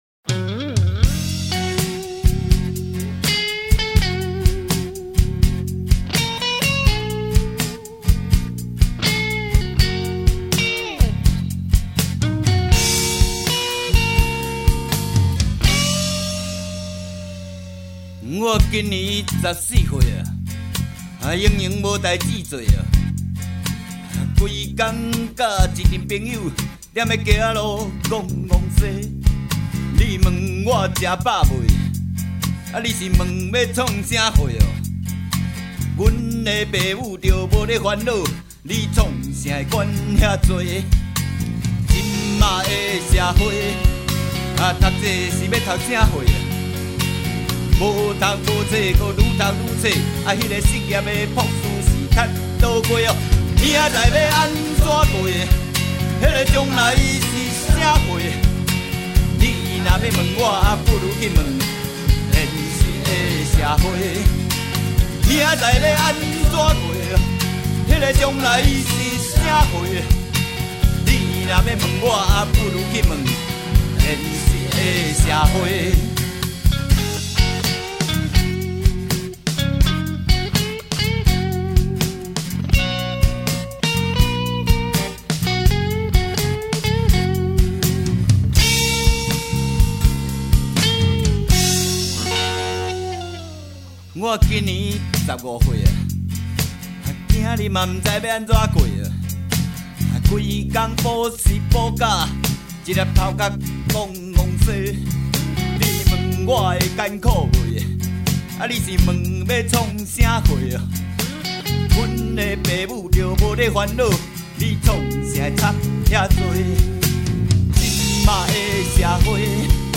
…正港原创 台语呛声… 一张社会写实音乐